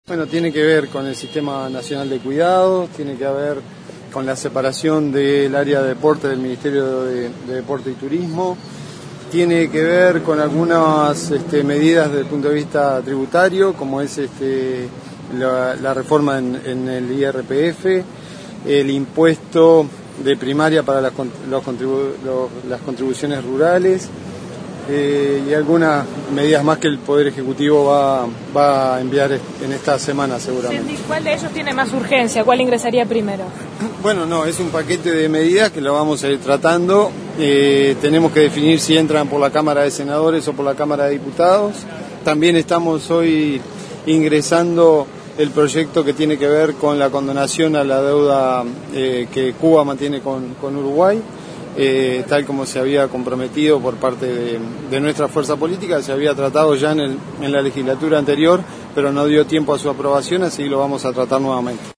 En rueda de prensa, antes de ingresar a sala, Sendic habló sobre varios temas, entre ellos los proyectos de ley que ingresarán al nuevo parlamento provenientes del Gobierno: